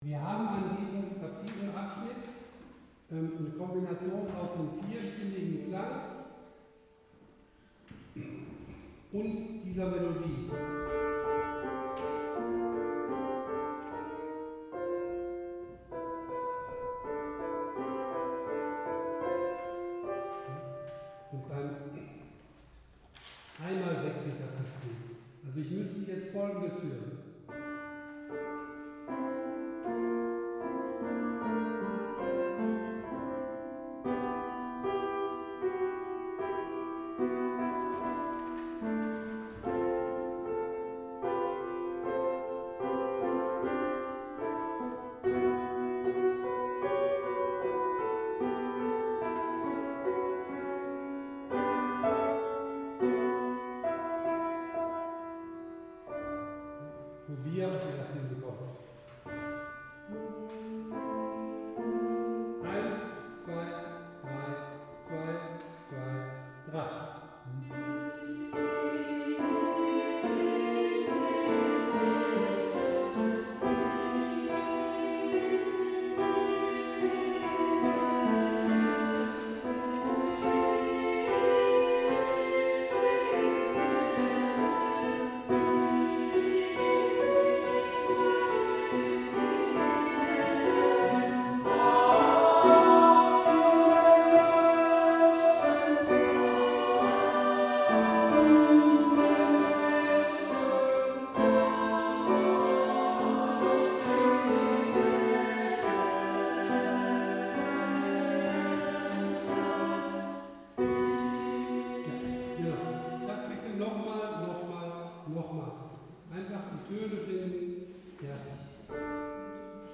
Gott-in-uns-T-151ff-Probe.mp3